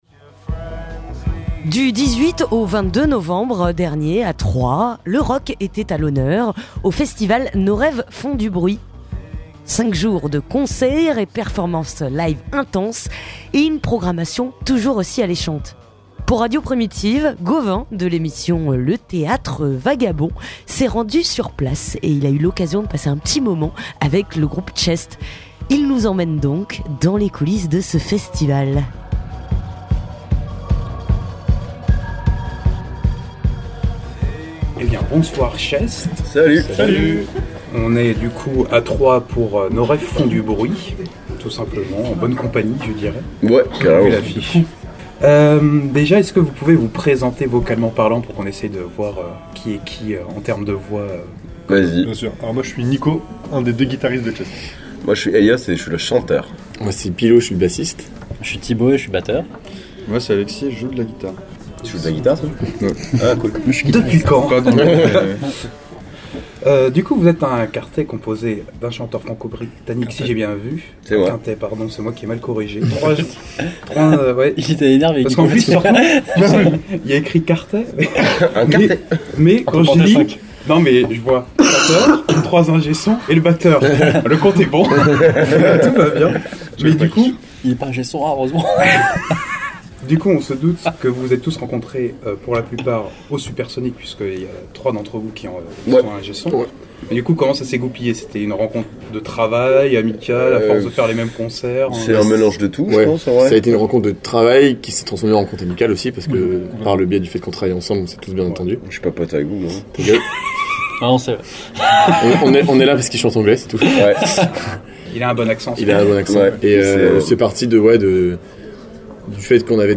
Interview de Chest. (25:21)
Il a pu passer quelques minutes avec le groupe parisien de post punk, Chest.